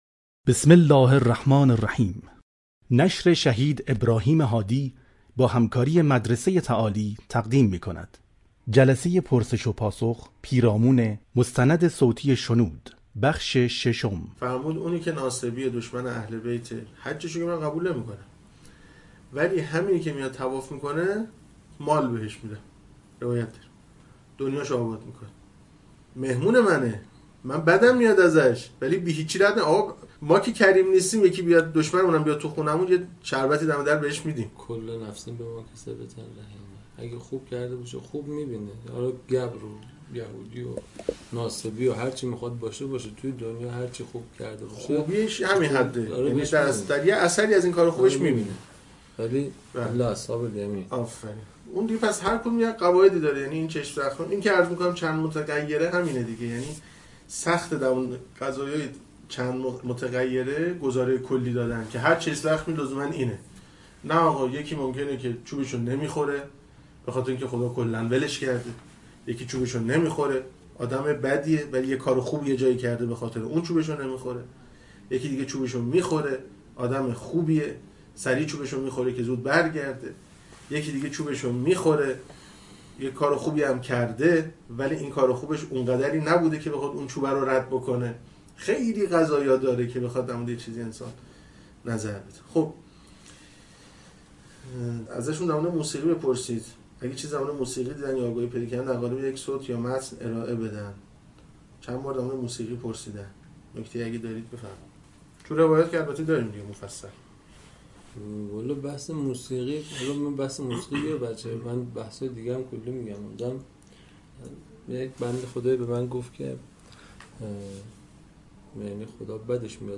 مستند صوتی شنود - جلسه 22 (بیست و دوم) / پرسش و پاسخ (6)